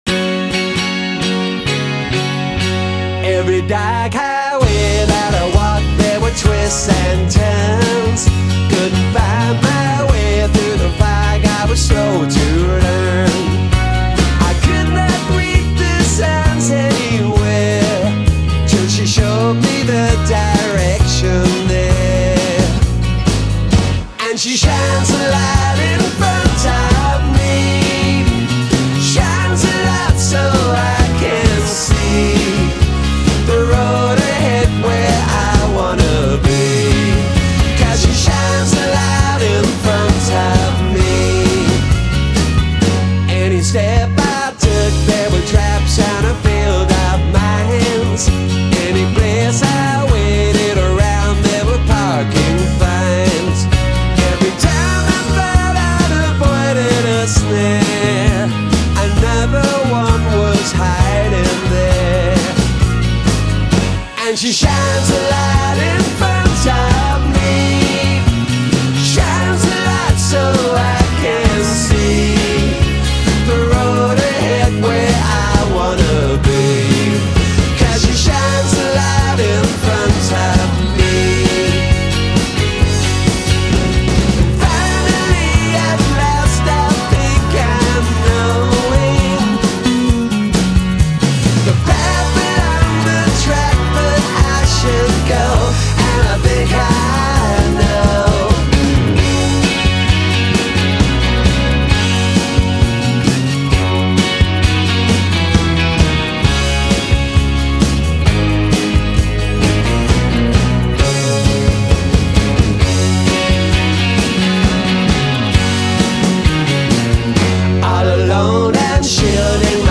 with its seductive lilting hooks